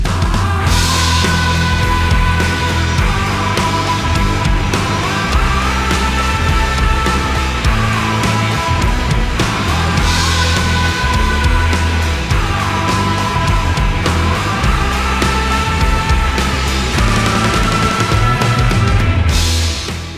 • Качество: 256, Stereo
Кино